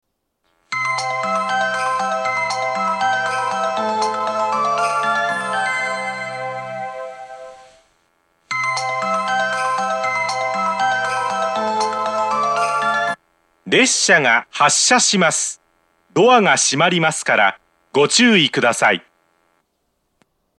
２番線発車メロディー 曲は「小川のせせらぎ」です。